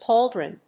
• IPA: /ˈpɔl.dɹən/